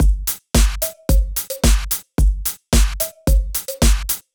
35 Drumloop.wav